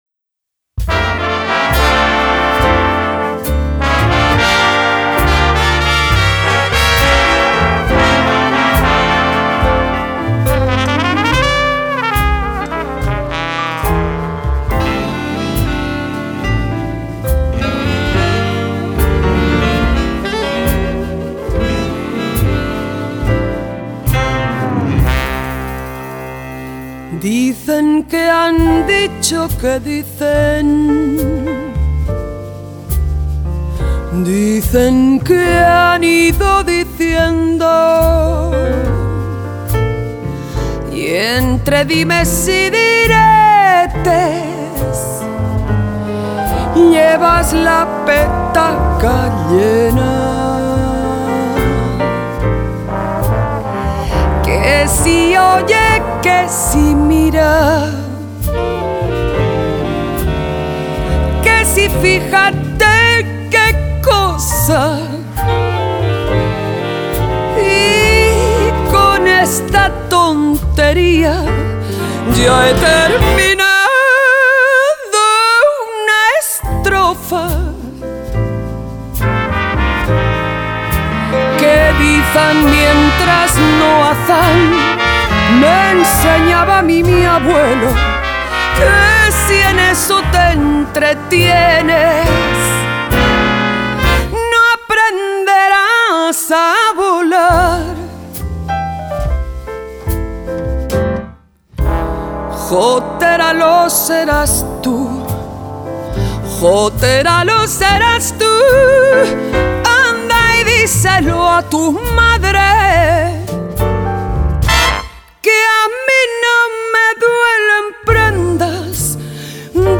Жанр: Folk.